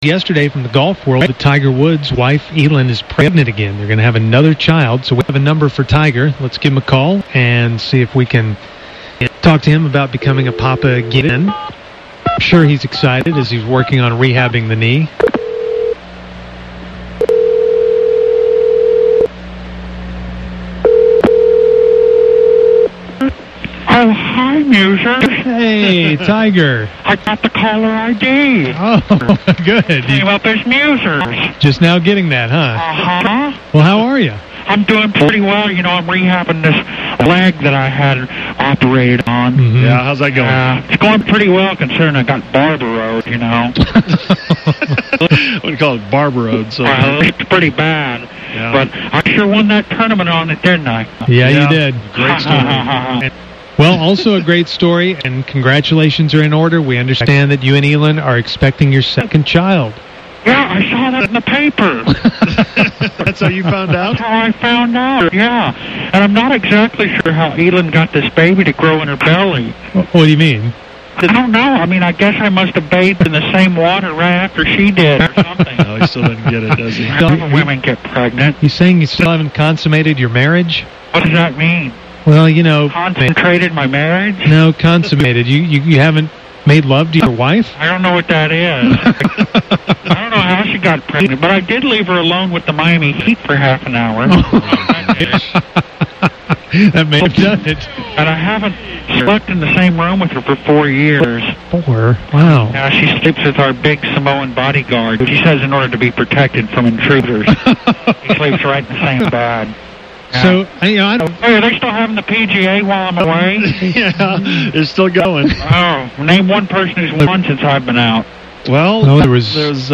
Fake Tiger Woods Tells Musers About Baby Number Two - The UnTicket
Always good to hear from the fake Tiger Woods.